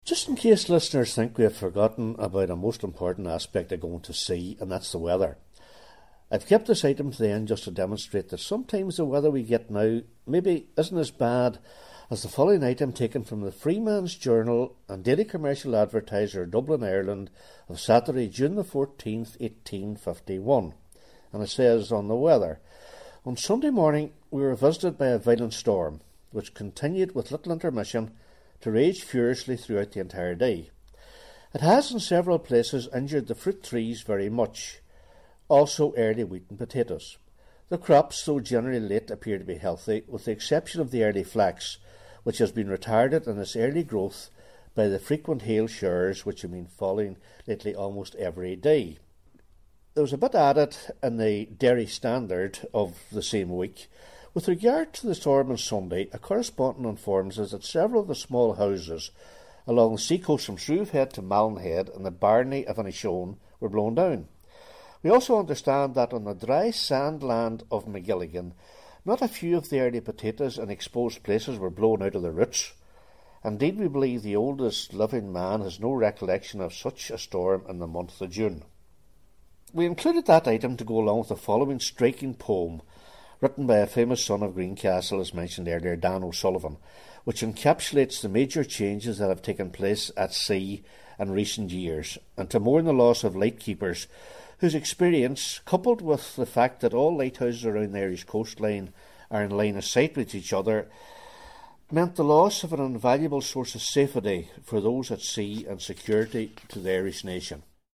reads eye-witness accounts from that time